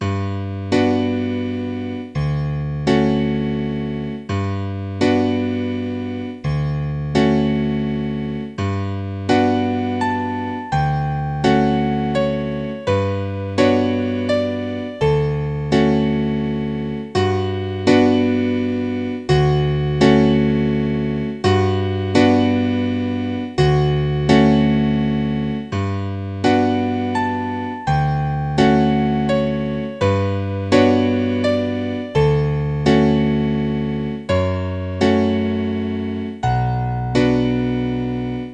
ピアノ独奏曲。
クラシック